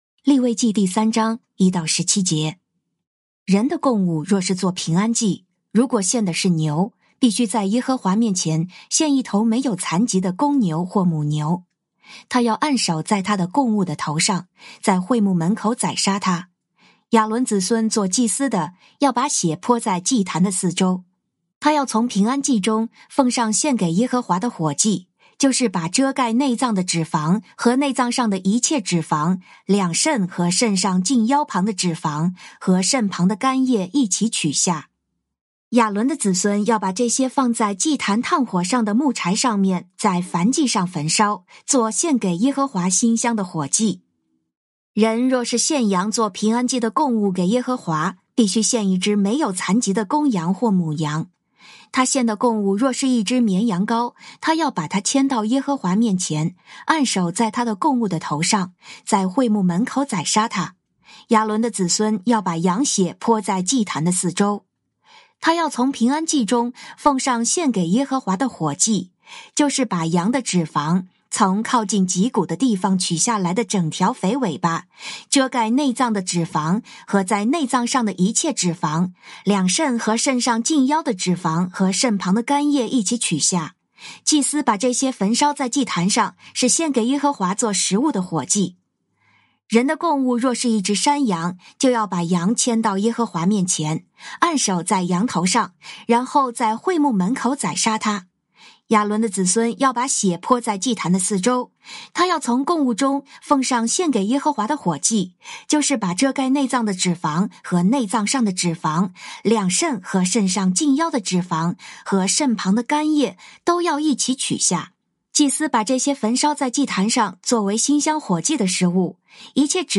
靈修分享：利3章1-17節「到了會幕門口....」
「天父爸爸說話網」是由北美前進教會Forward Church 所製作的多單元基督教靈修音頻節目。